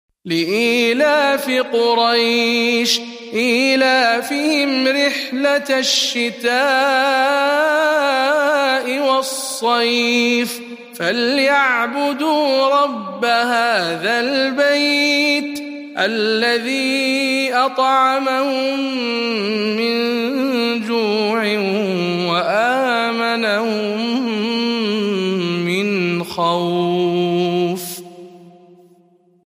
سورة قريش برواية شعبة عن عاصم